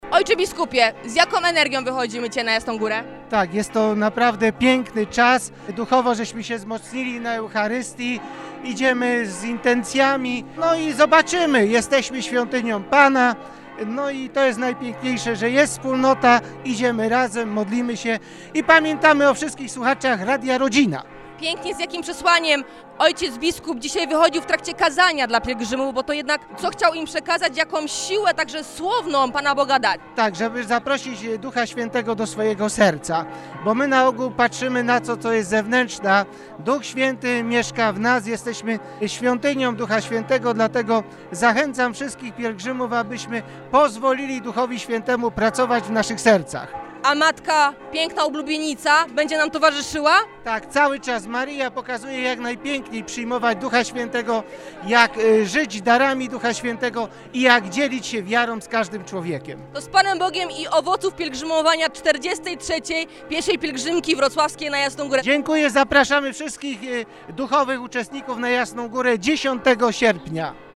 Ojciec biskup Jacek Kiciński wygłosił kazanie w trakcie Mszy św. w katedrze wrocławskiej na rozpoczęcie 43. Pieszej Pielgrzymki Wrocławskiej na Jasną Górę. – Zaprośmy Ducha św. do naszego serca – mówił ojciec biskup do pielgrzymów i do radiosłuchaczy.